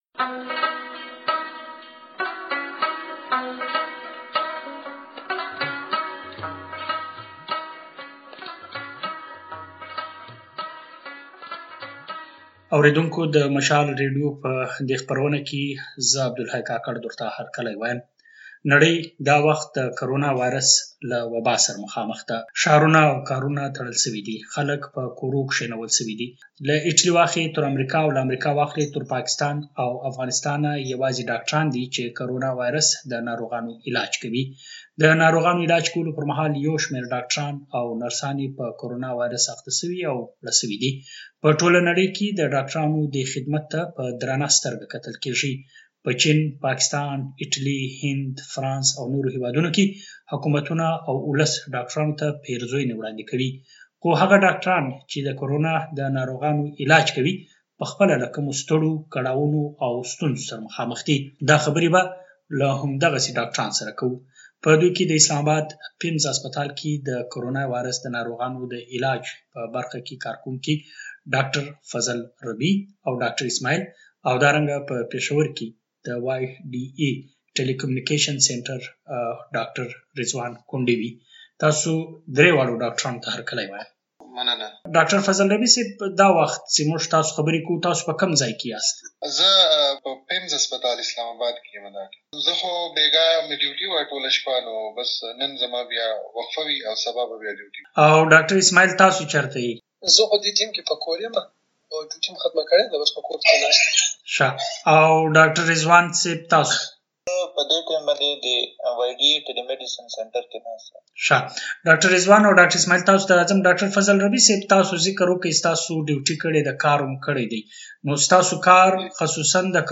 کورونا وايرس پخپله د دغو ډاګټرانو ژوند څومره اغېزمن کړی دی.؟ په دې اړه د مشال راډيو ځانګړی بحث واورئ.